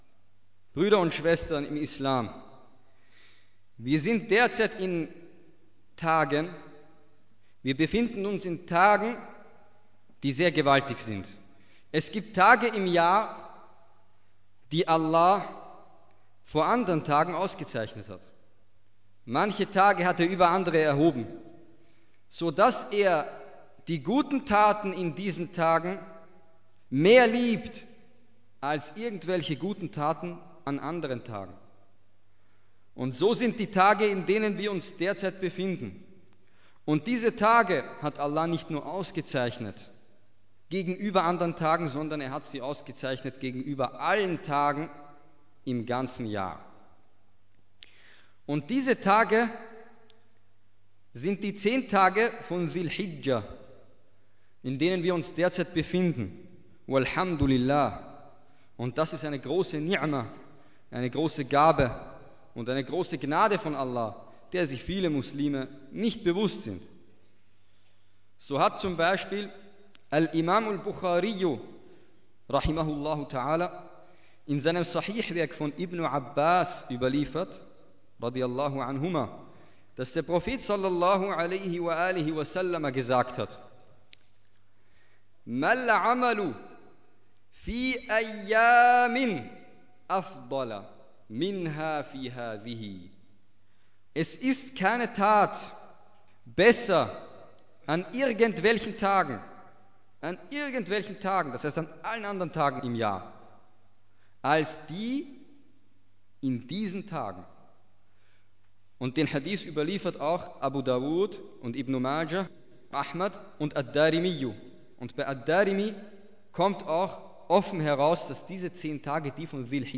Freitagsansprache: Die besten Tage des Jahres – Die zehn Tage von Dhu l-Hijjah
Als Erleichterung für den deutschsprachigen und auch den nicht-muslimischen Zuhörer wurde es vorgezogen, eine gekürzte Fassung zur Verfügung zu stellen, bei der vor allem längere arabische Teile weggekürzt wurden. Die eigentliche Ansprache besteht aus zwei Teilen, dazwischen eine kurze Pause. Am Ende folgt das Gebet mit Rezitation von Versen aus dem Koran, welche meistens auch einen Bezug zum Thema haben oder in der Ansprache erwähnt werden. Die im deutschen Hauptteil der Ansprache zitierten arabischen Quelltexte unterbrechen den Redefluss nicht nennenswert und werden immer übersetzt.